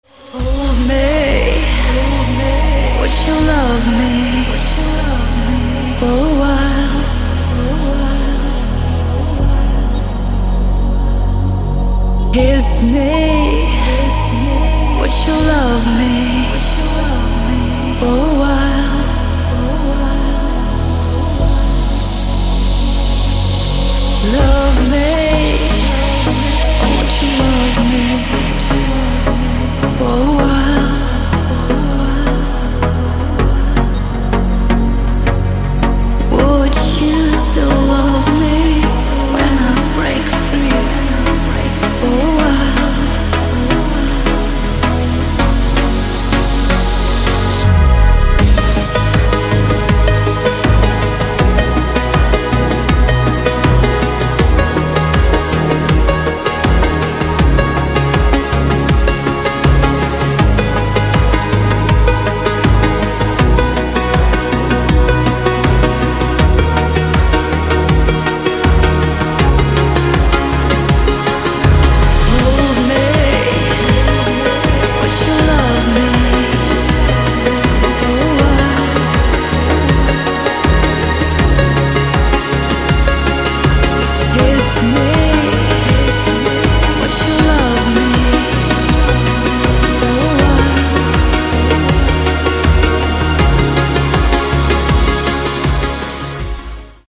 • Качество: 160, Stereo
красивые
женский голос
dance
медленные
приятный женский голос